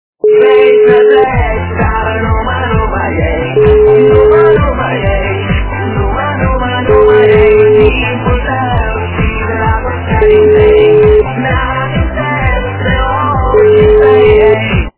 западная эстрада
качество понижено и присутствуют гудки.